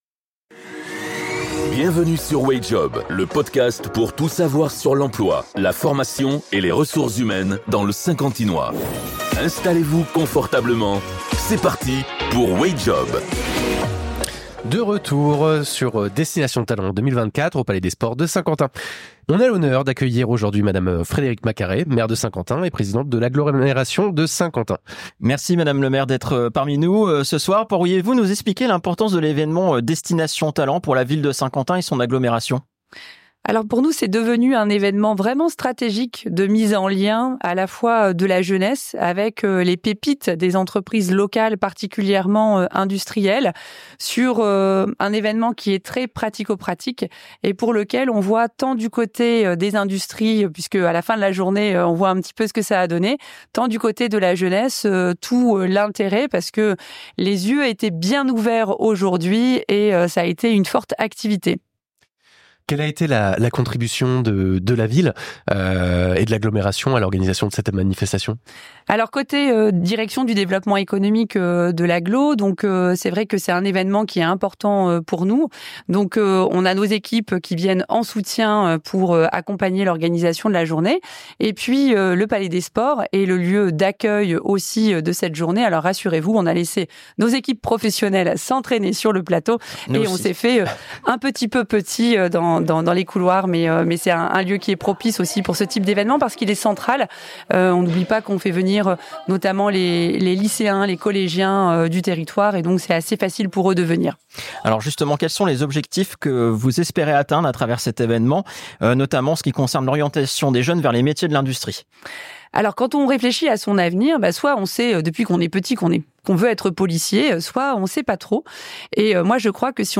Episode 20 - Entretien avec Madame Frédérique Macarez : Vision et Engagement